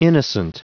Prononciation du mot innocent en anglais (fichier audio)
Prononciation du mot : innocent